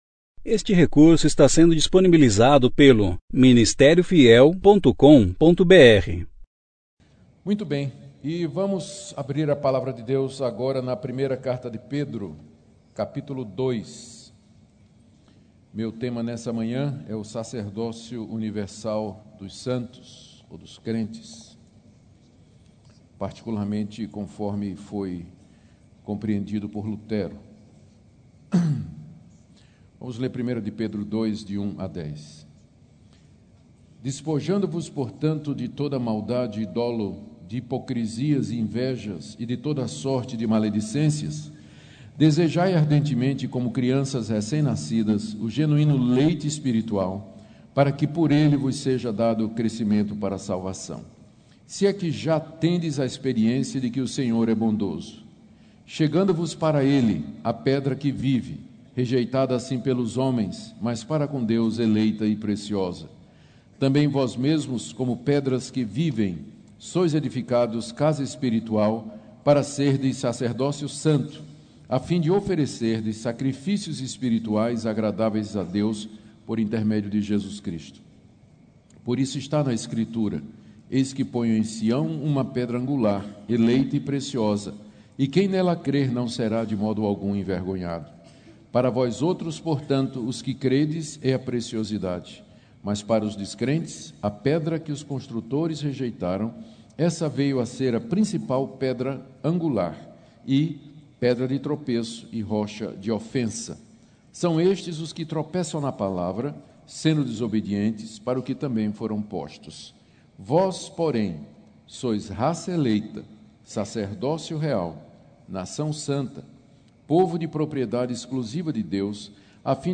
Conferência: 33ª Conferência Fiel para Pastores e Líderes – Brasil Tema: Protestantes Ano